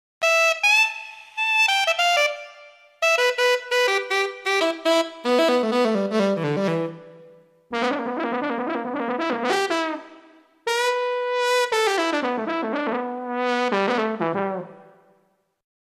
Софтовый синтезатор также заметно шумит.
Эти фрагменты были записаны в Wav-файл на звуковой карте SW1000XG и конвертированы в mp3 с помощью программы CDex Version 1.30.
Соло альт саксофона, сменяющееся тромбоном.
Заметна разница в реверберации.